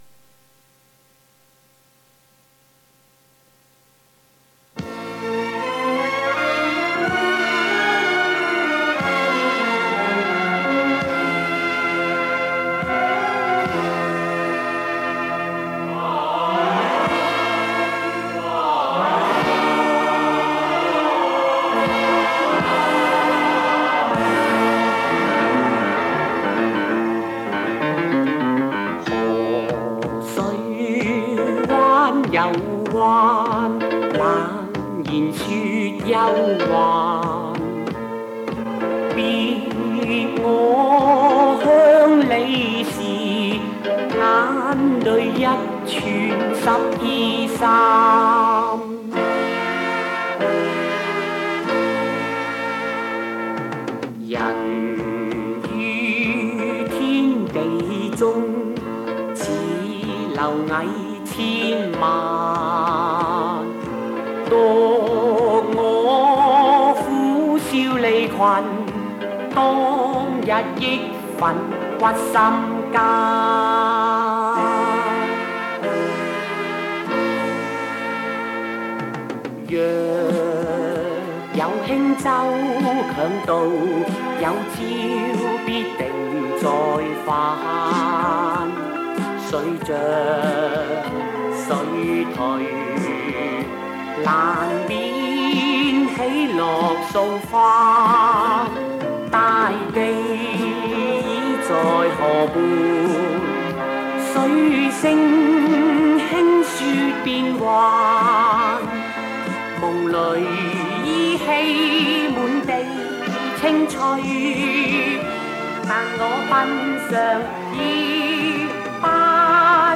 主题歌